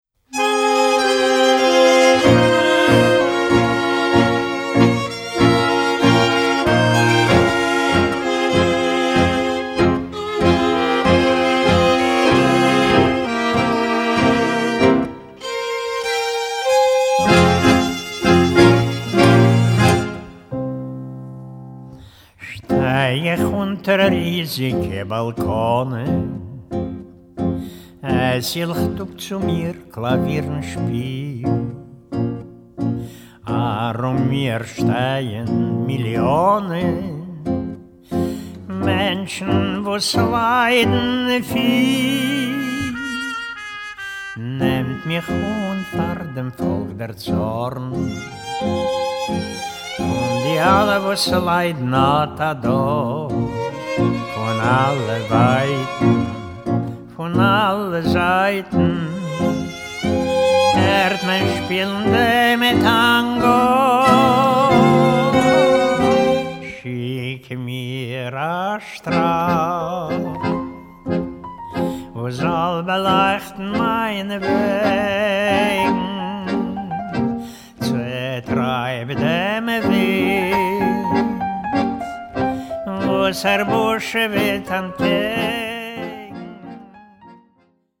A self-taught, natural lyrical tenor
Genres: Yiddish, World.
vocals
viola, violin
violoncello
piano, accordion
clarinet, alto saxophone
trumpet
mandolin
guitar
Recorded in Vienna, October 12-15, 2011